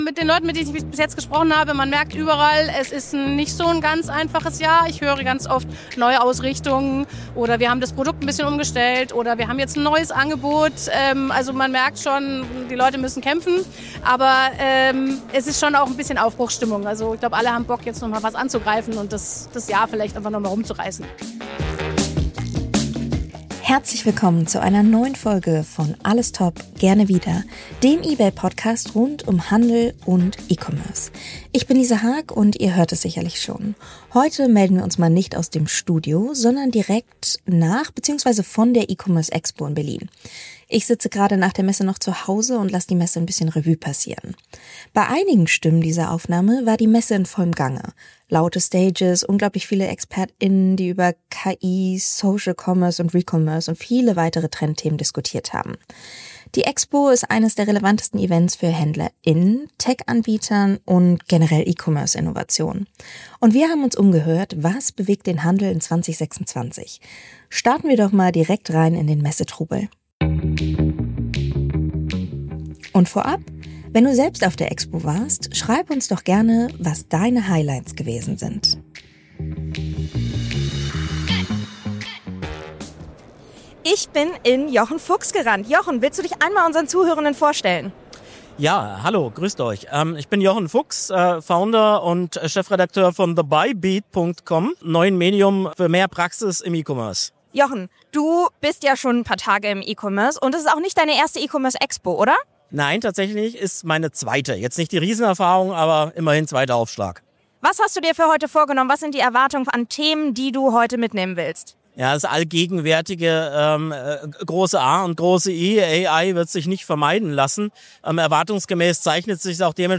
Beschreibung vor 1 Monat Volle, trubelige Berliner Messehallen und alle sprechen von KI. In dieser Folge von „Alles top. Gerne wieder!“ nehmen wir dich mit auf die E-Commerce Berlin Expo, die am 17. und 18. Februar in Berlin stattfand. Statt Studio gibt’s Messeatmo, authentische Statements von Händler*innen, E-Commerce-Expert*innen und Branchen-Insidern. Die Themen auf der Expo waren KI & Agentic Commerce, Neuausrichtung von Händler*innen bei anhaltender Investitionszurückhaltung, warum Online-Marktplätze bleiben und wie eBay Live den neuen Selling & Shopping Hype befeuert.